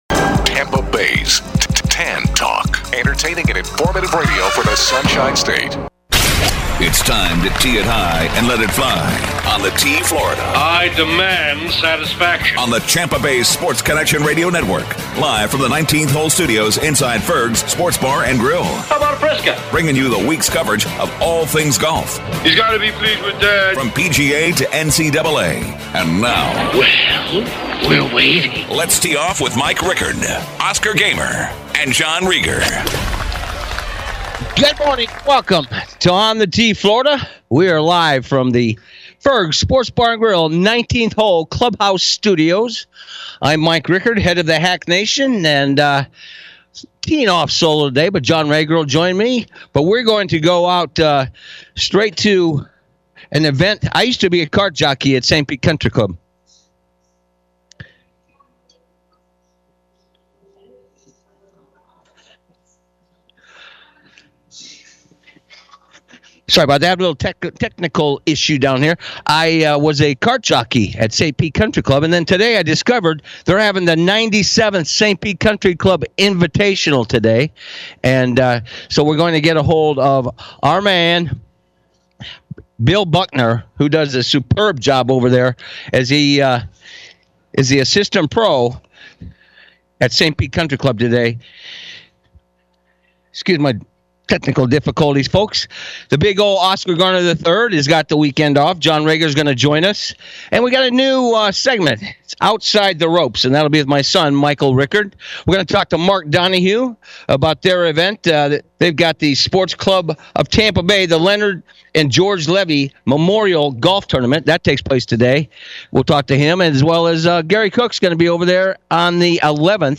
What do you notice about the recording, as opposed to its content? "On The Tee Florida" 1-7-23; Live from Ferg's w